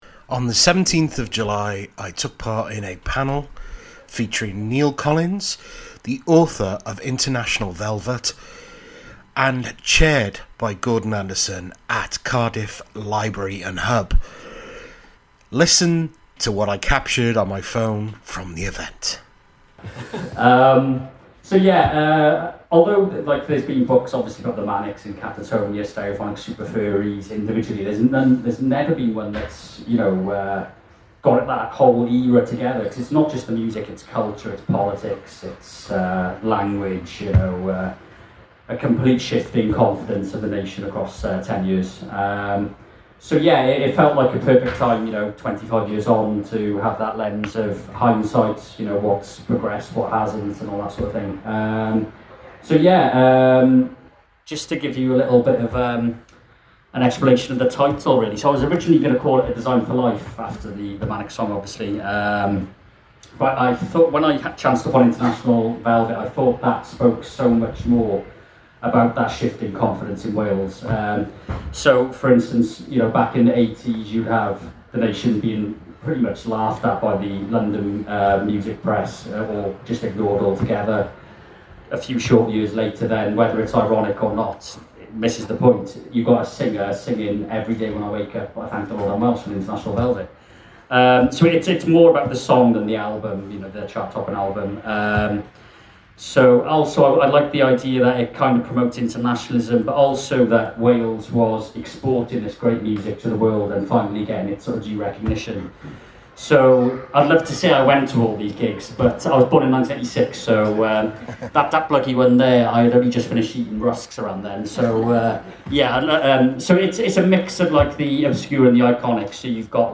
Listen to a phone recording from the event above.